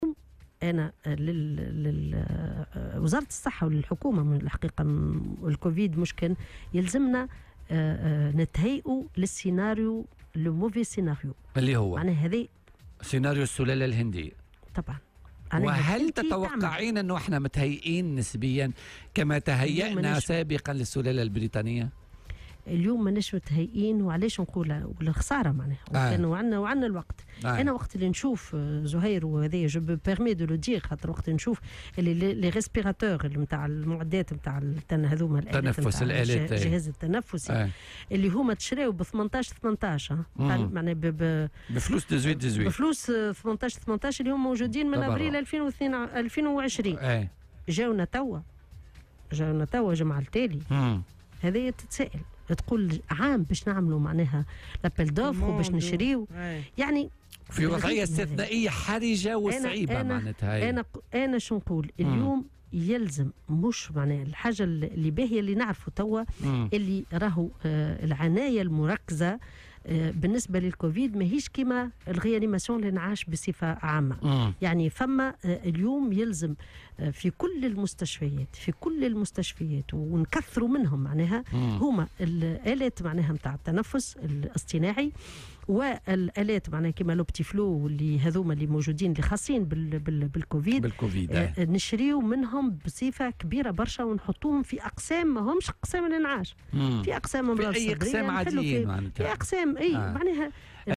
وأضافت في مداخلة لها اليوم في برنامج "بوليتيكا" أنه لابد من تعزيز مختلف المستشفيات بكامل تراب الجمهورية بآلات التنفس الاصطناعي وغيرها من المعدات الخاصة بمرضى الكوفيد.